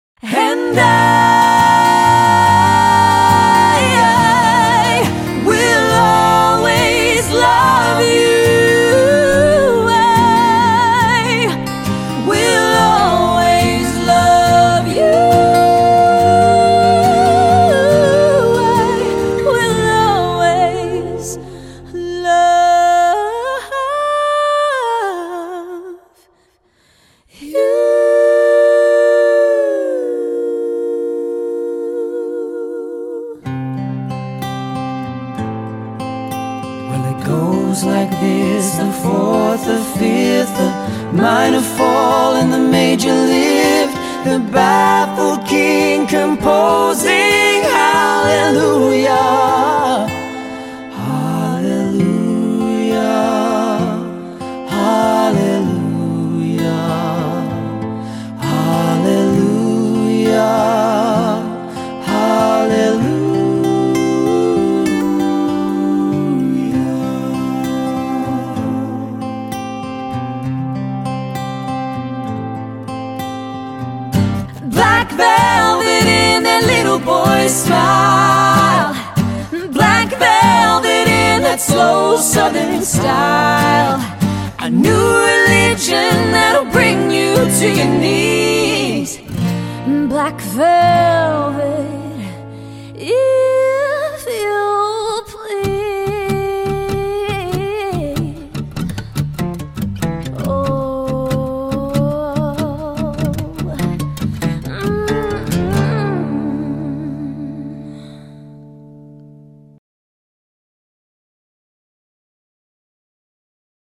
Two guitars | 3 part harmonies
a captivating musical trio